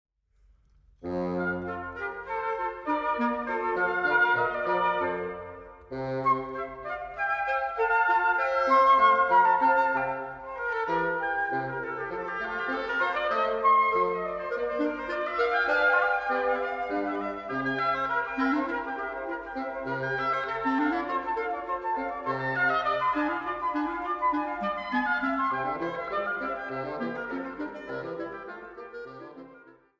Flöte
Oboe und Englischhorn
Klarinette
Fagott
Harfe